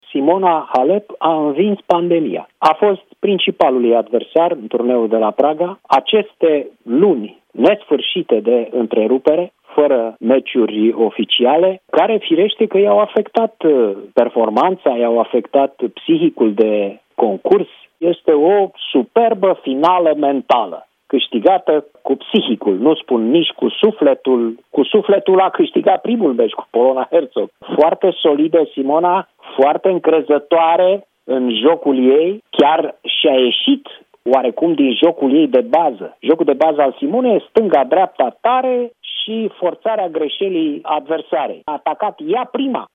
”Este o superbă finală mentală!”, a spus, la Europa FM, gazetarul Cristian Tudor Popescu despre victoria pe care Simona Halep a obținut-o, în turneul de la Praga.